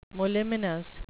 moliminous (mo-LIM-in-uhs) adjective
Pronunciation: